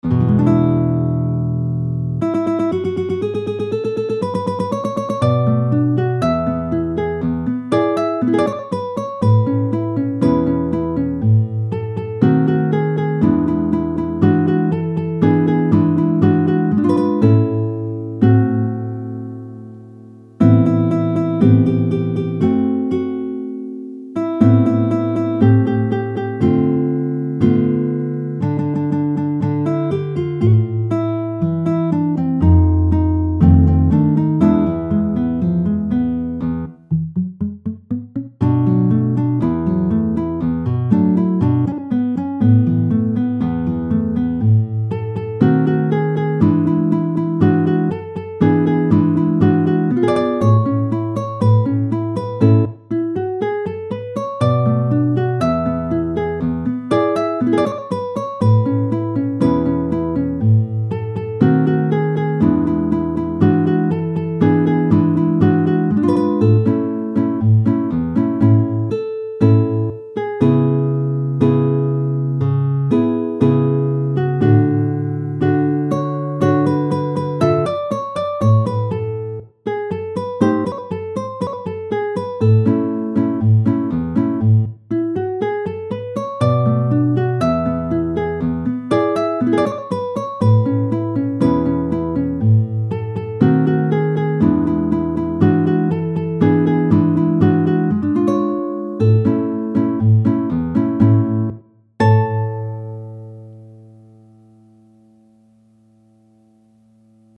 Altre Canzone napoletana per chitarra